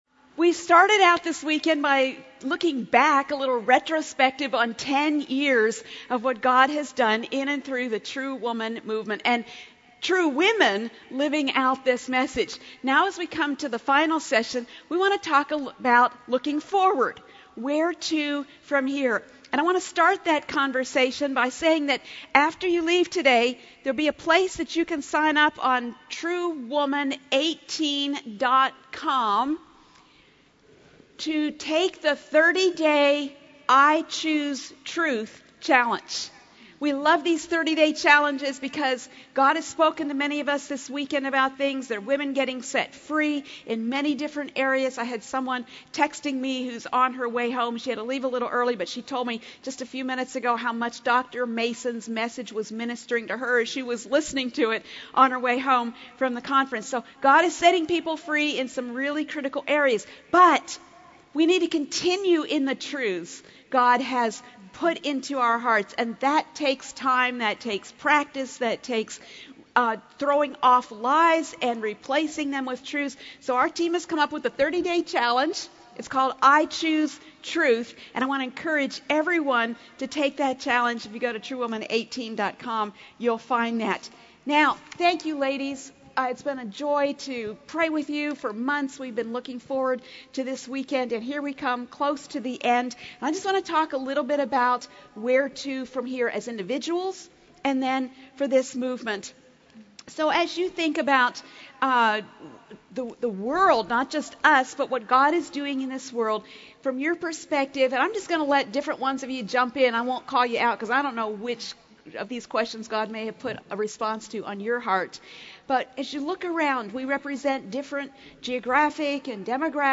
Panel 2: Where to from Here?